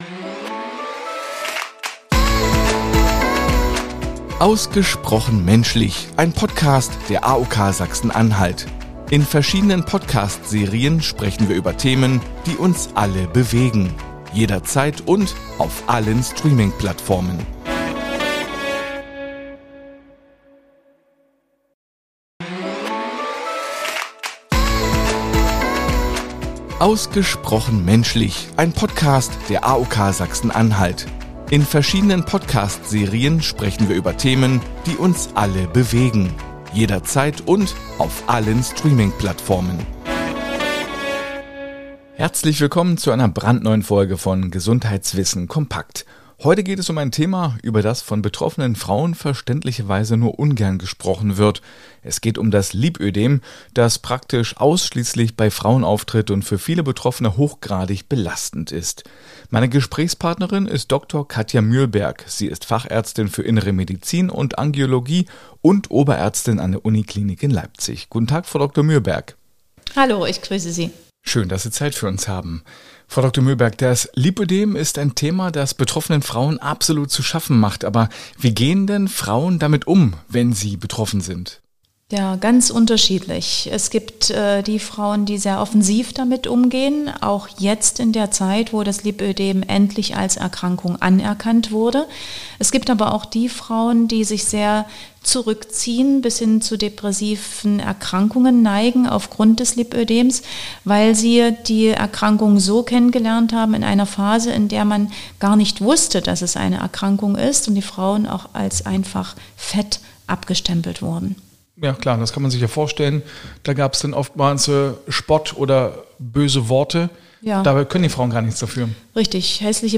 Sie ist Fachärztin für Innere Medizin und Angiologie.